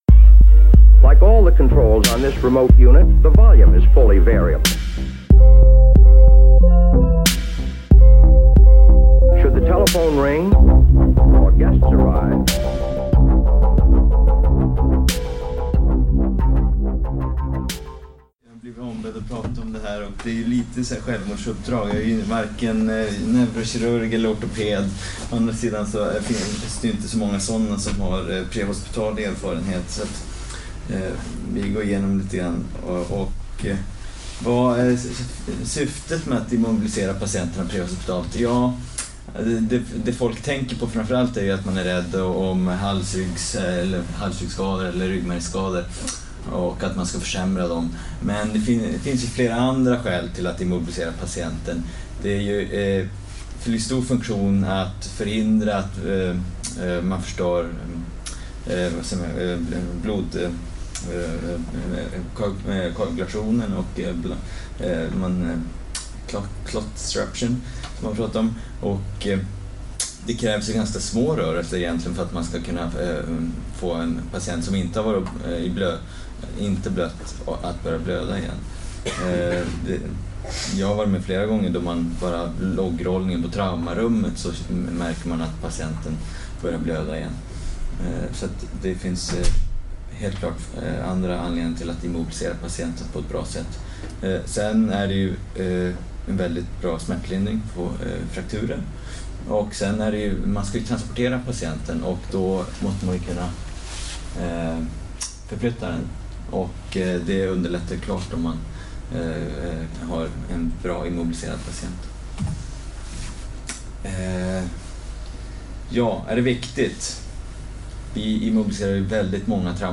Föreläsningen hölls på en ST-fredag om prehospital sjukvård och katastrofmedicin.
Föreläsningen hölls den 1 december 2017 på Karolinska sjukhuset i Solna.